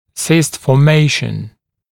[sɪst fɔː’meɪʃn][сист фо:’мэйшн]формирование кисты